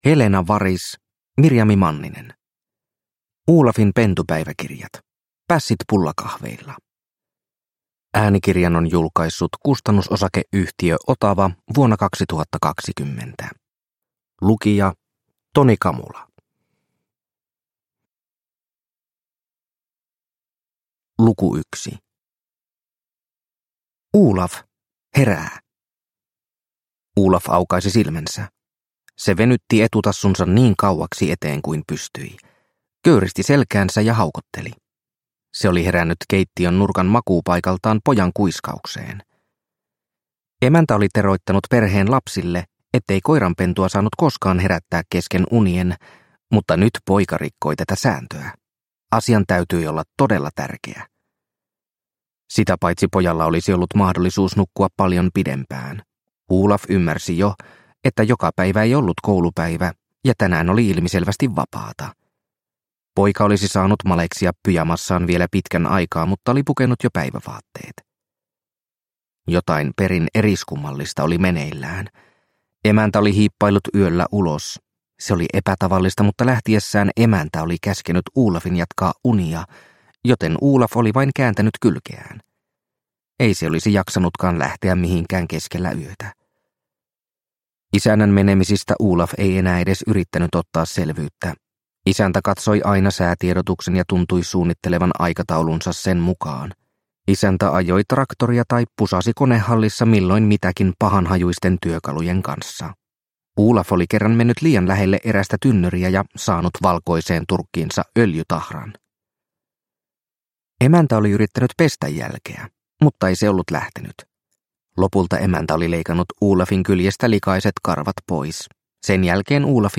Olafin pentupäiväkirjat - Pässit pullakahveilla – Ljudbok – Laddas ner